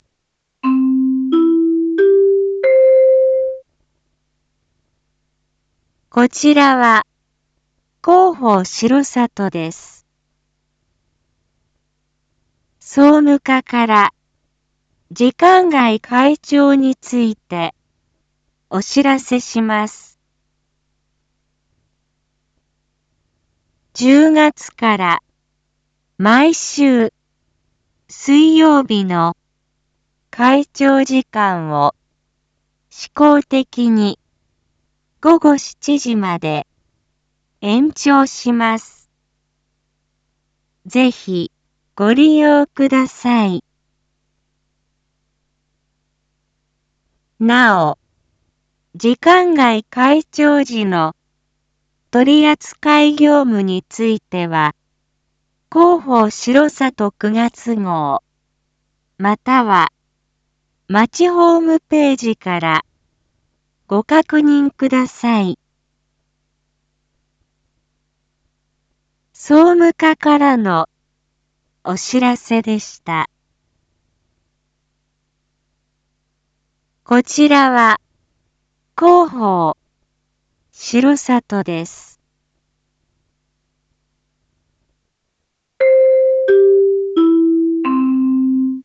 一般放送情報
Back Home 一般放送情報 音声放送 再生 一般放送情報 登録日時：2023-10-02 19:01:30 タイトル：時間外開庁について② インフォメーション：こちらは、広報しろさとです。